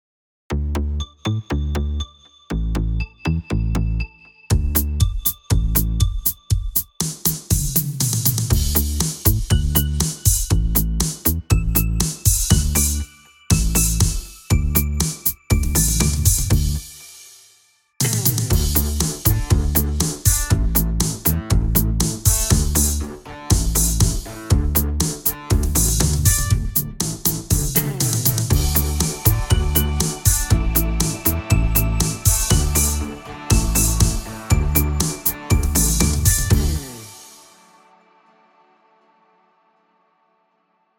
Jingle
Free jingle for your commercial ads and spots